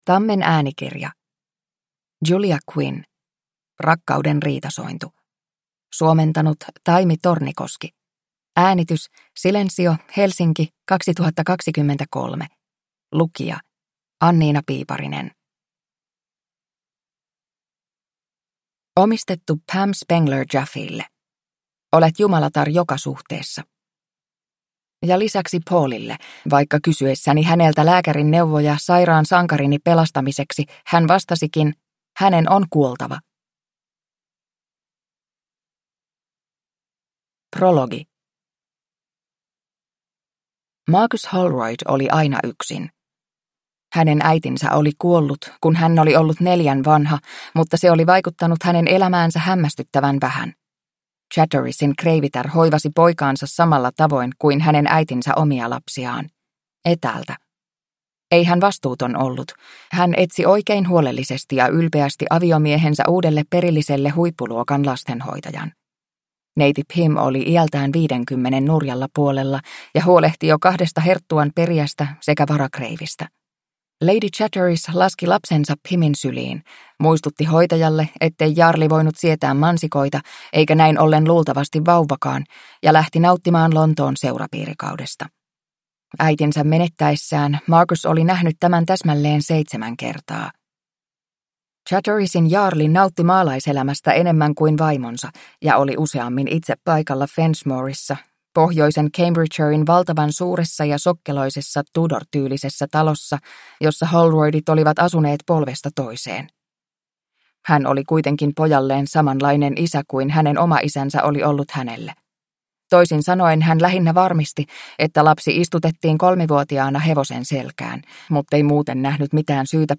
Rakkauden riitasointu – Ljudbok – Laddas ner